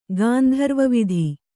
♪ gāndharva vidhi